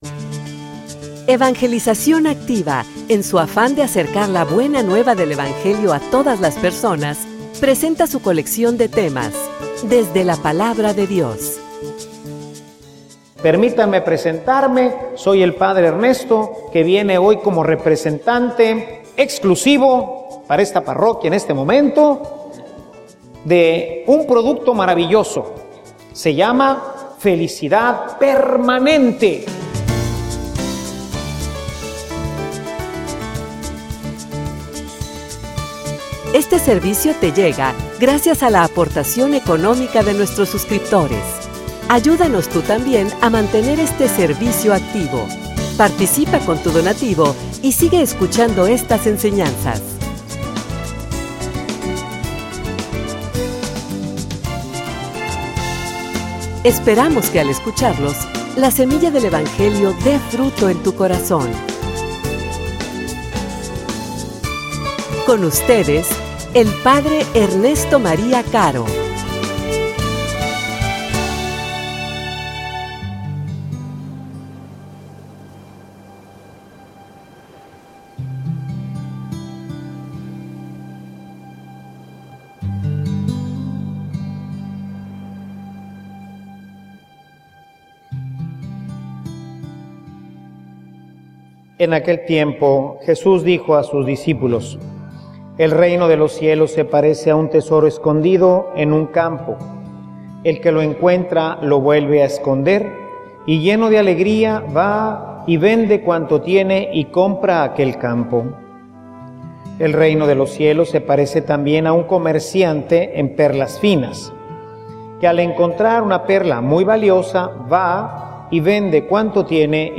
homilia_Un_producto_que_vale_la_pena.mp3